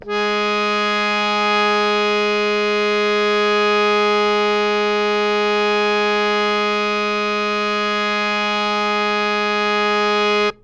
harmonium
Gs3.wav